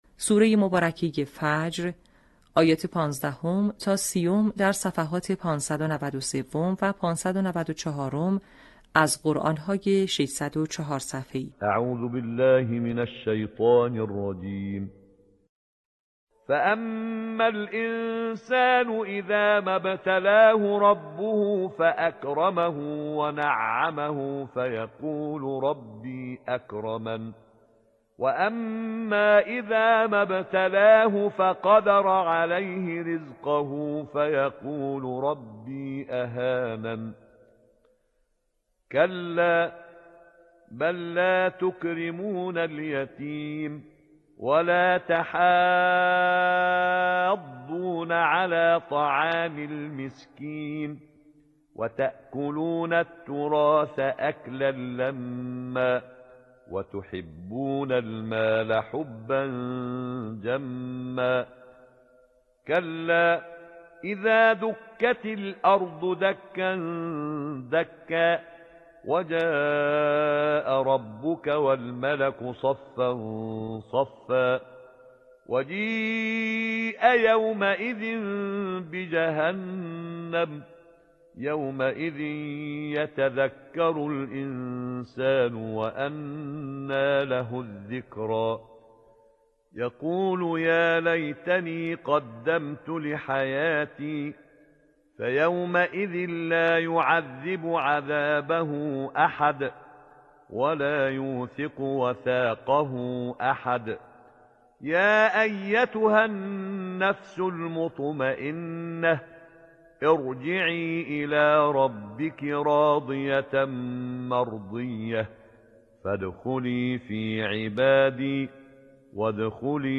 آموزش حفظ جزء 30 آیات 15 تا 30 سوره فجر